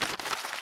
x_enchanting_scroll.7.ogg